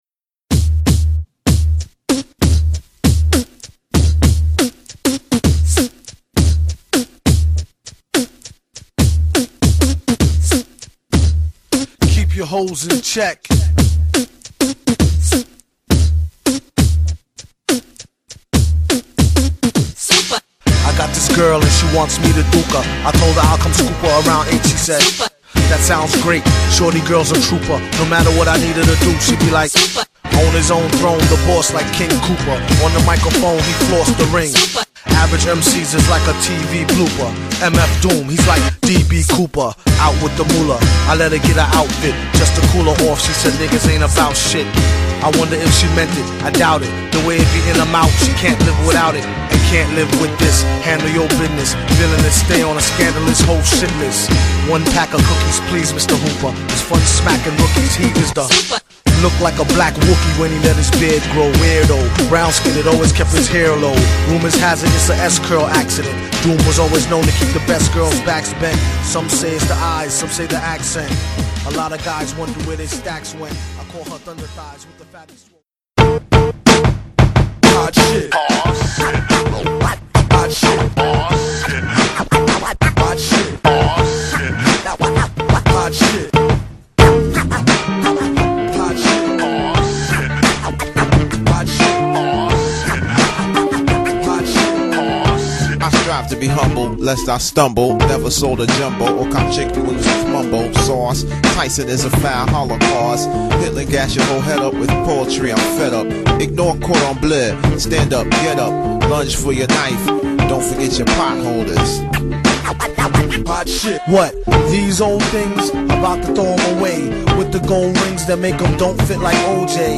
ザックリしつつも中毒性高いループにオリジナリティ溢れるラップ